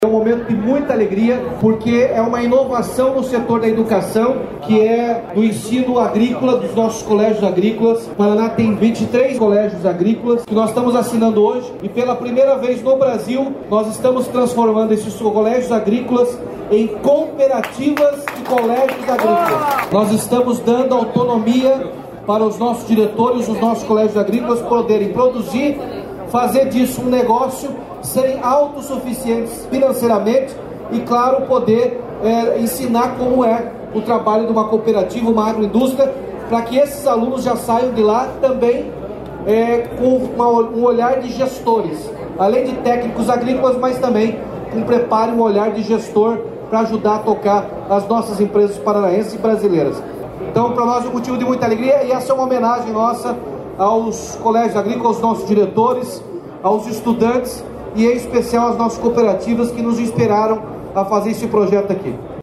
Sonora do governador Ratinho Junior sobre o decreto que regulamenta cooperativas nos colégios agrícolas e florestais